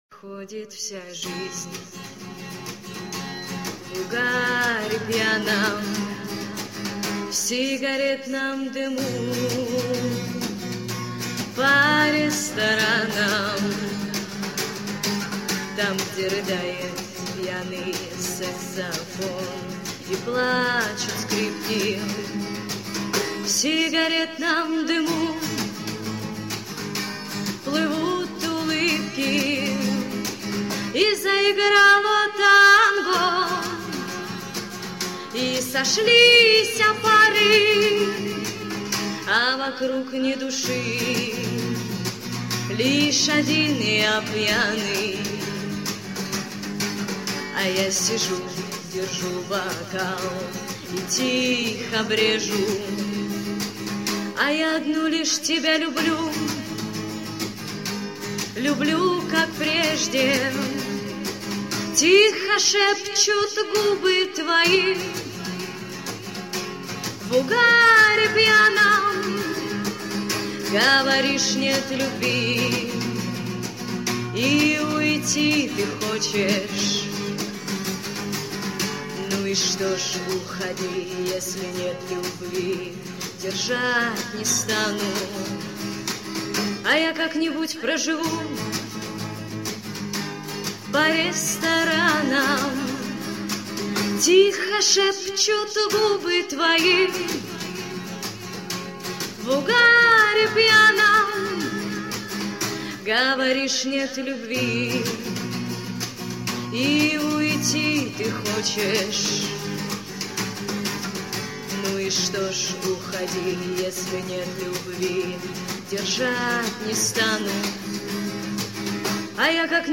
Под гитару
Pod-gitaru.mp3